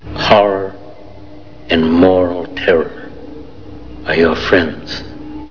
Sound Bites: